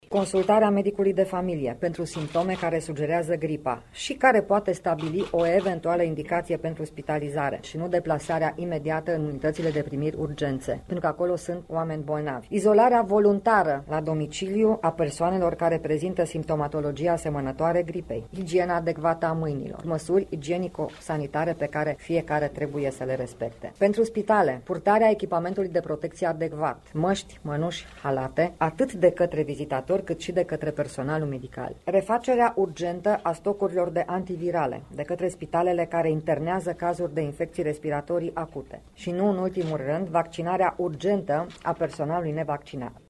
Ministrul Sorina Pintea, despre măsurile preventive ce trebuie luate:
stiri-26-ian-Pintea.mp3